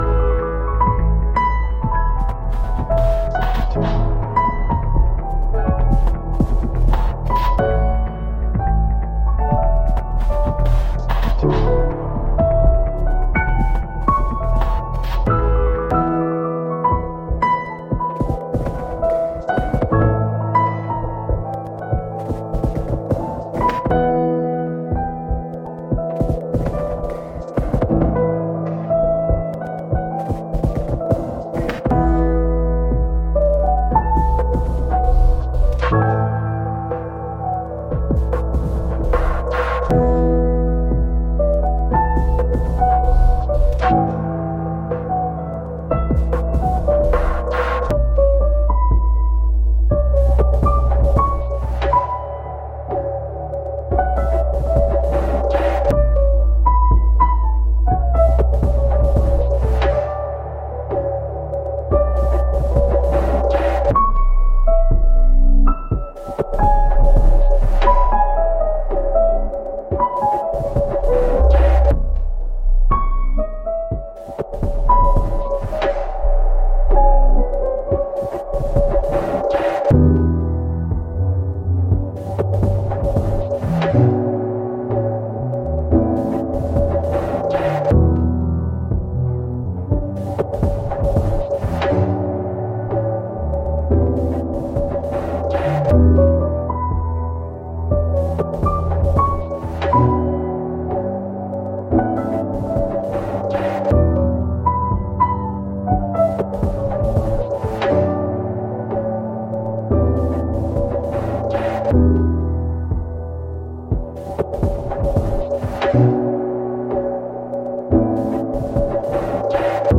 dunkle Stimmung in einer Bar